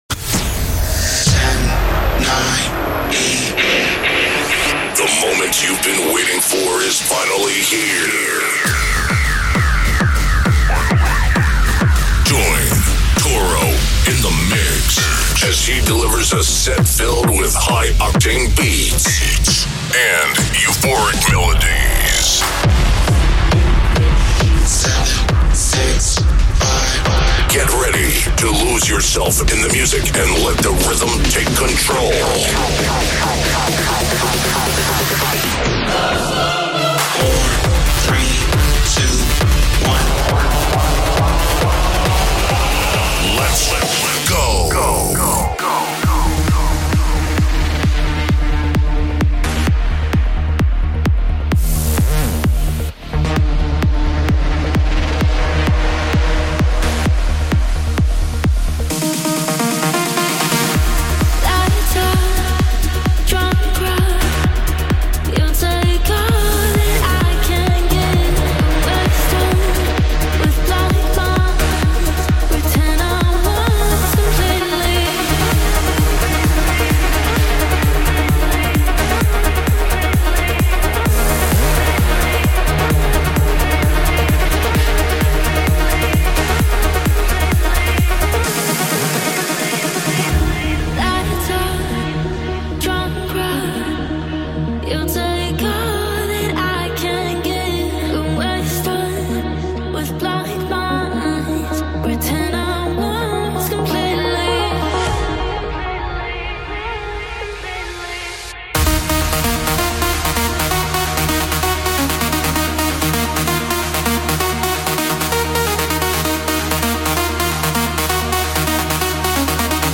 " the ultimate dance and trance music podcast.